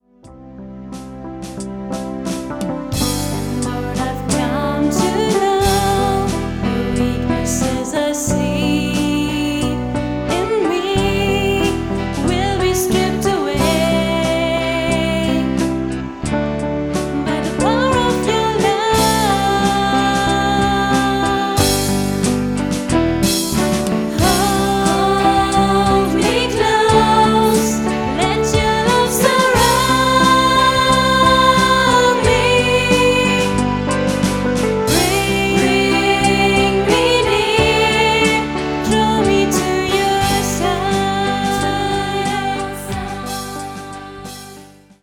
Genre: NGL.